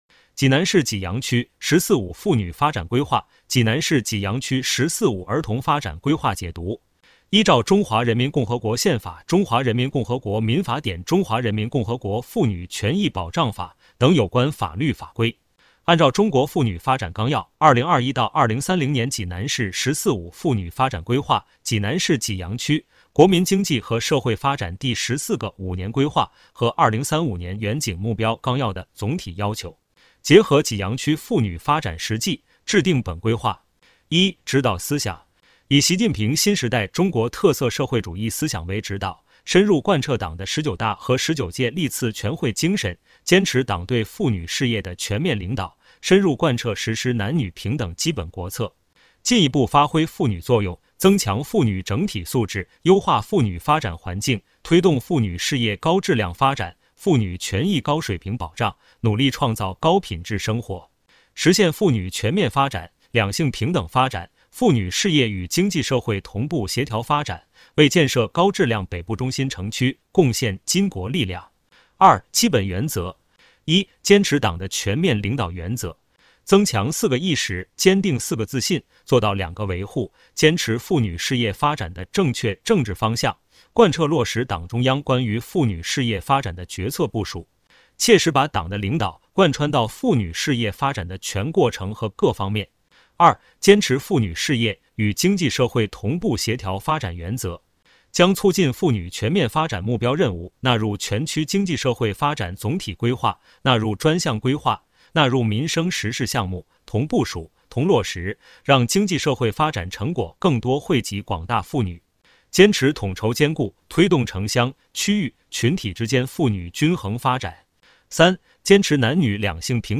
济南市济阳区人民政府 - 有声朗读 - 【音视频解读】《济南市济阳区“十四五”妇女发展规划》和《济南市济阳区“十四五”儿童发展规划》